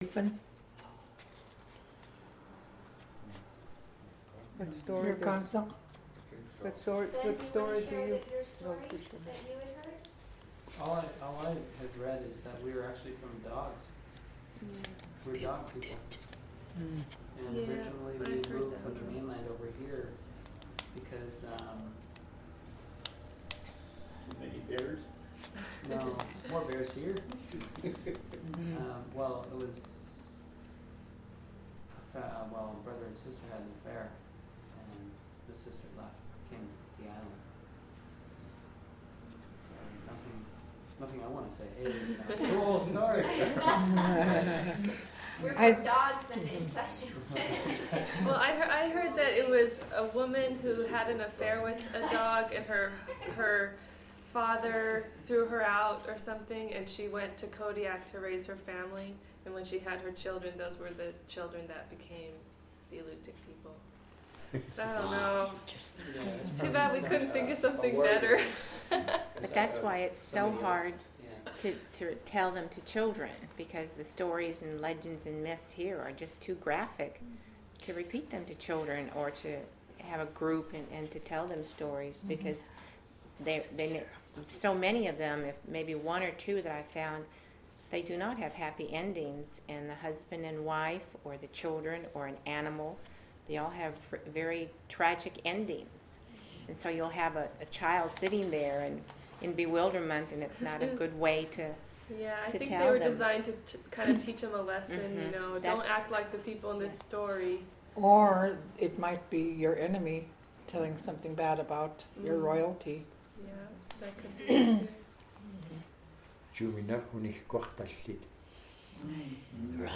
Access audio Description: Original Format: MiniDisc (AM470:124A) Migration: CD (AM470:124B) Recording length (file size, time): 721.6 MB; 1:08:11 Location: Location Description: Kodiak, Alaska